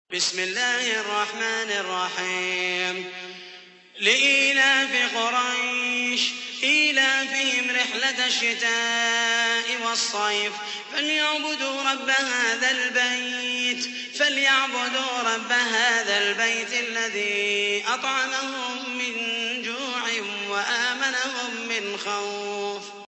تحميل : 106. سورة قريش / القارئ محمد المحيسني / القرآن الكريم / موقع يا حسين